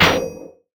TM88 DeepSnare.wav